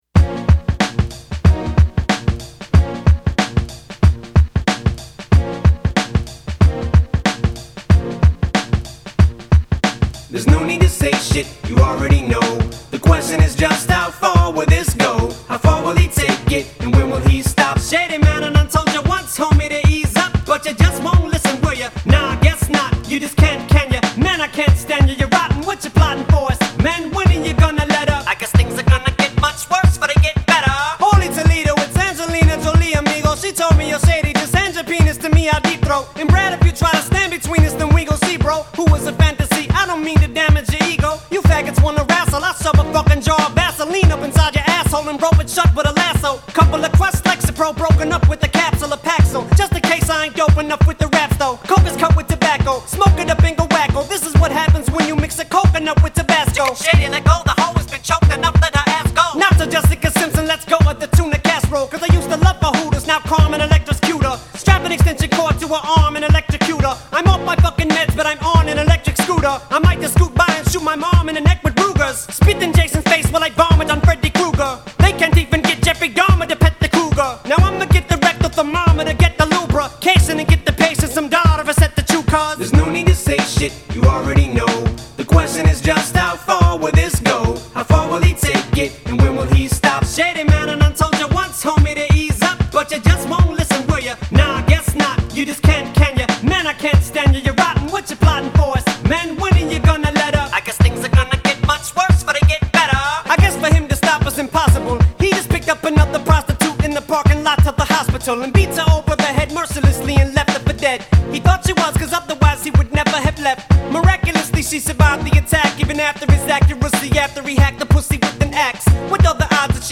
в жанре хип-хоп